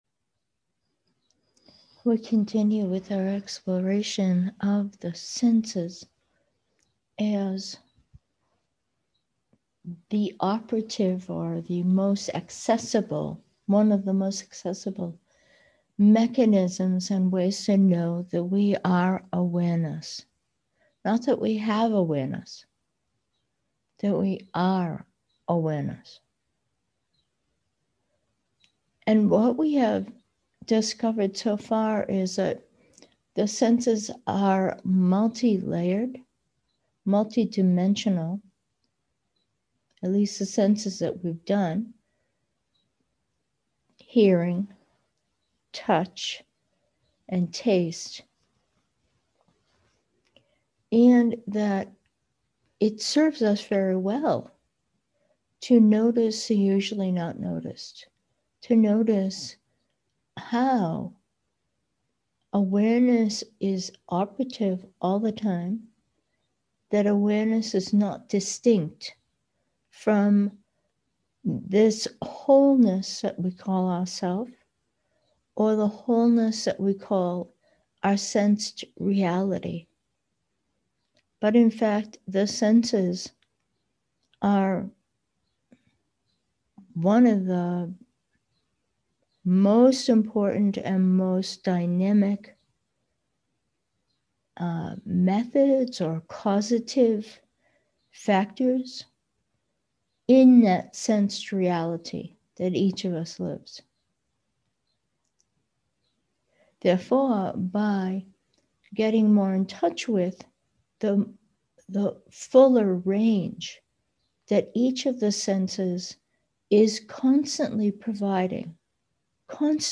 Meditation: visual/seeing and awareness 1